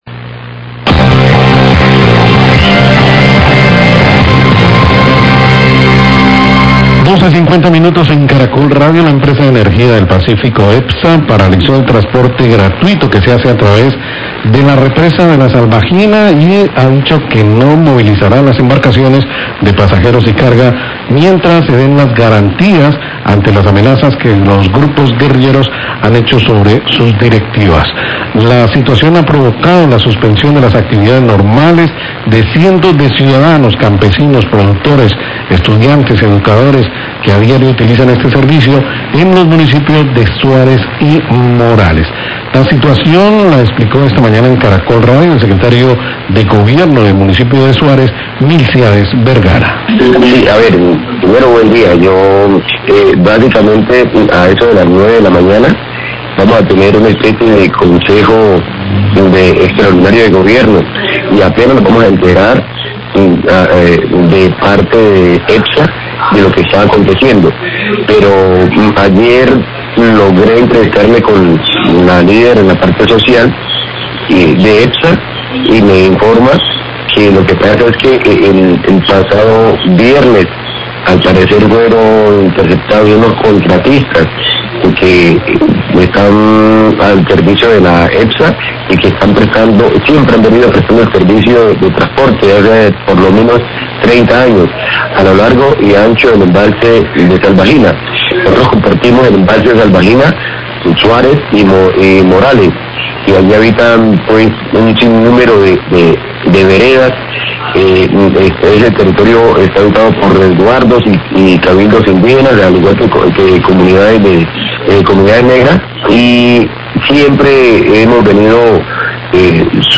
Radio
Epsa paralizó el transporte gratuito que se hace a través de la represa La Salvajina y ha dicho que no movilizará las embarcaciones mientras no se den las garantías ante las amenazas que los grupos guerrilleros han hecho sobre sus directivas. Declaraciones del Secretario de Gobierno de Suárez, Milciades Vergara.